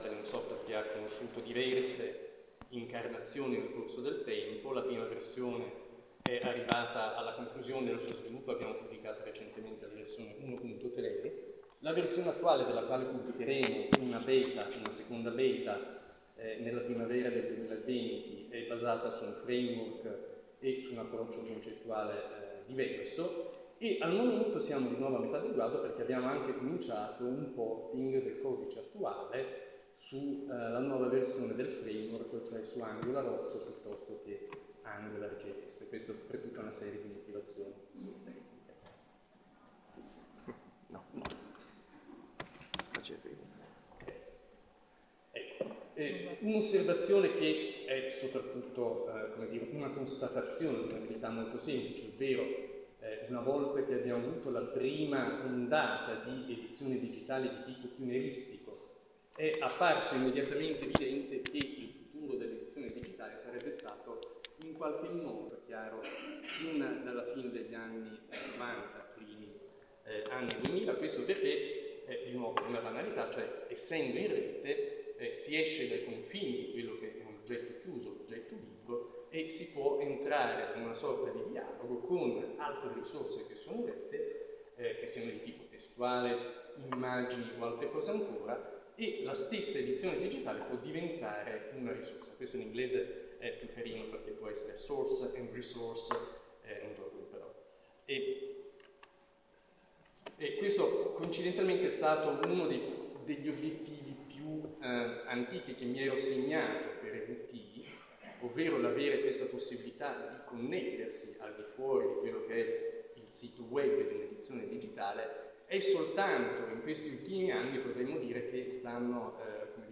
Talk at the AIUCD 2020 conference on the integration of LOD and IIIF with EVT and more generally with TEI